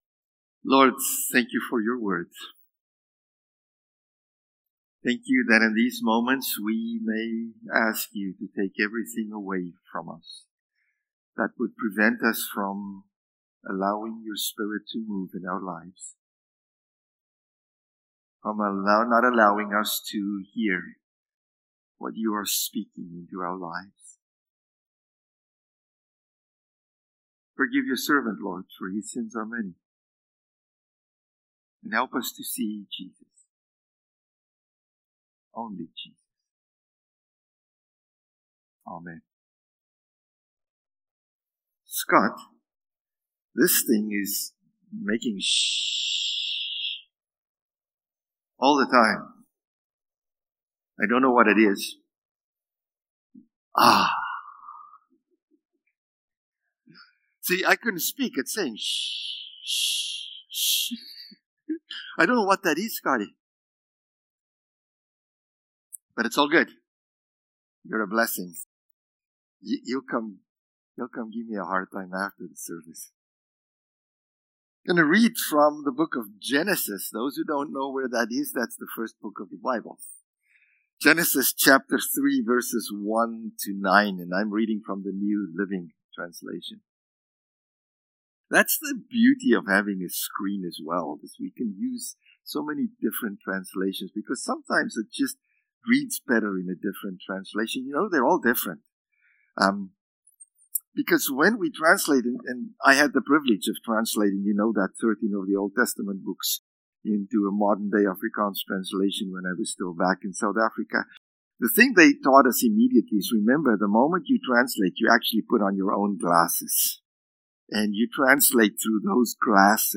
February-8-Sermon.mp3